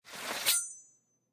combat / weapons / default_swingable / draw1.ogg
draw1.ogg